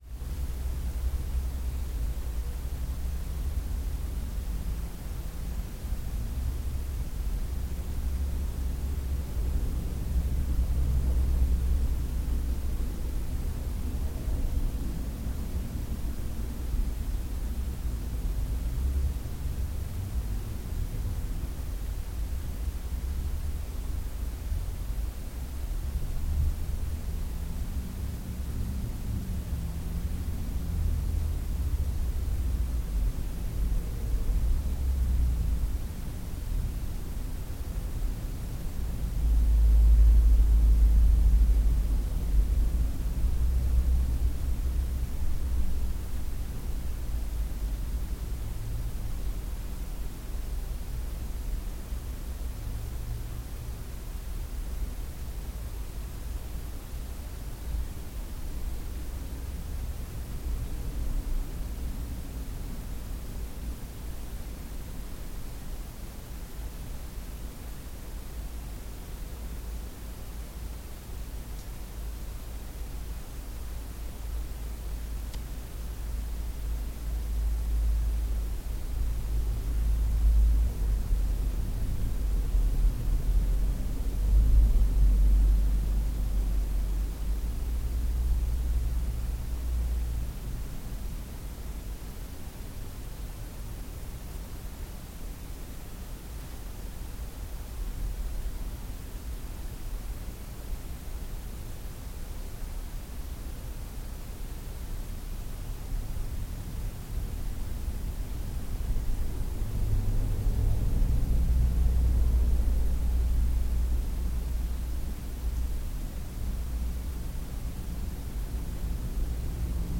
Звуки комнаты
Продолжительный гул монтажного помещения